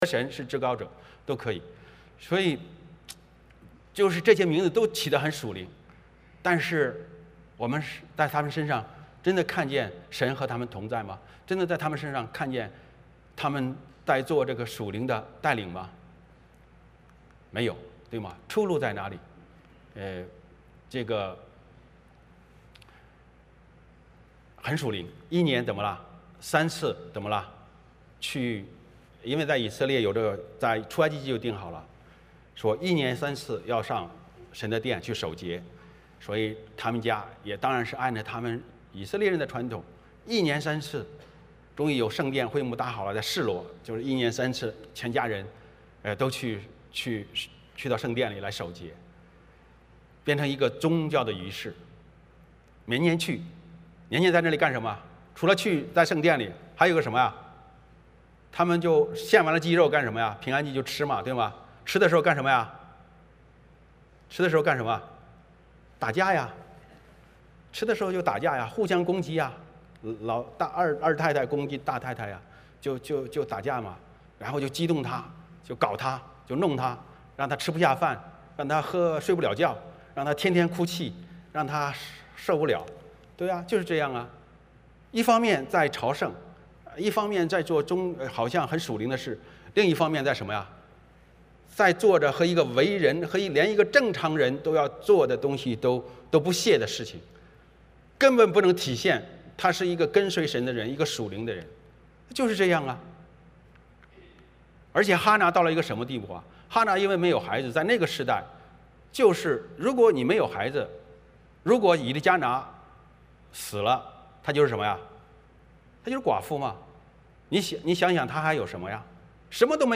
欢迎大家加入我们国语主日崇拜。
1-20 Service Type: 主日崇拜 欢迎大家加入我们国语主日崇拜。